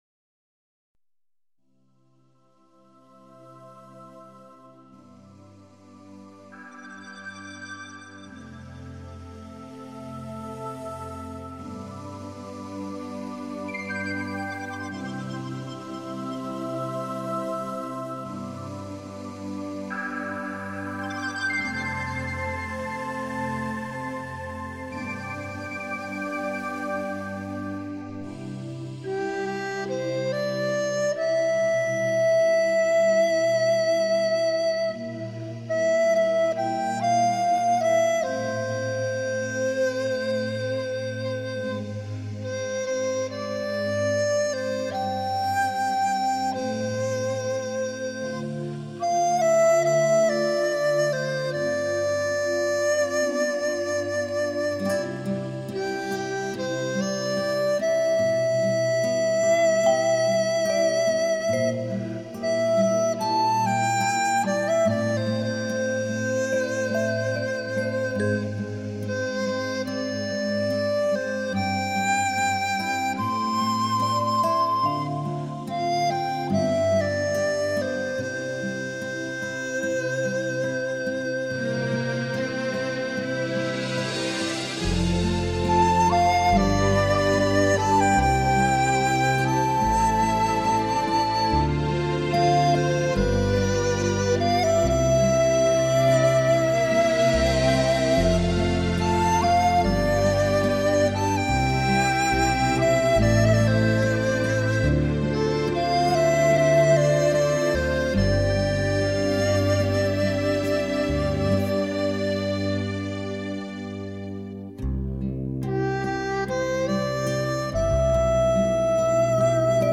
清脆通透的笛音，传来声声竹韵。
仔细听听这些曲子，都具有朴实无华，明丽清纯的特色。
这种“竹韵”与吉他以及电子合成器的粘合力很强！录音臻发烧境界，开创民族乐器的新纪元。
笛子
大提琴
吉他